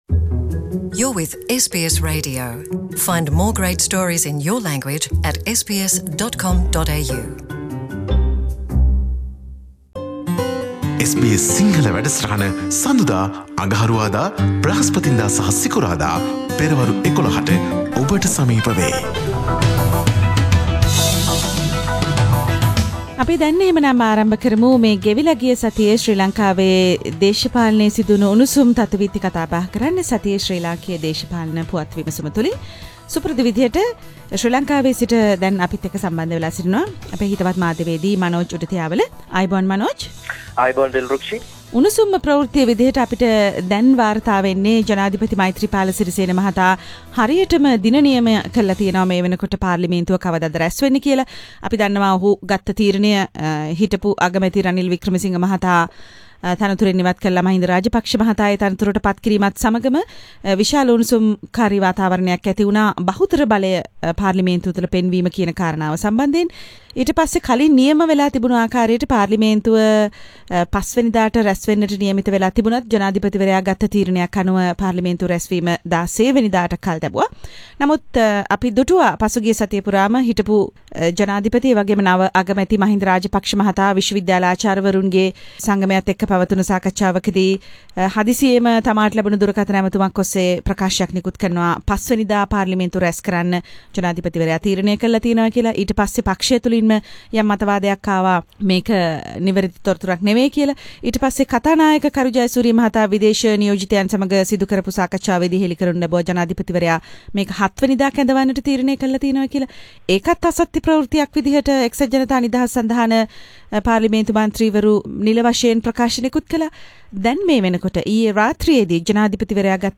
පුවත් සමාලෝචනය